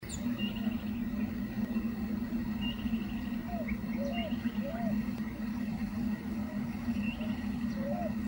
Bran-colored Flycatcher (Myiophobus fasciatus)
Location or protected area: Reserva Natural Urbana La Malvina
Condition: Wild
Certainty: Recorded vocal